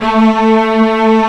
Index of /90_sSampleCDs/Roland L-CD702/VOL-1/CMB_Combos 1/CMB_Arco_Marcato
STR STRING0D.wav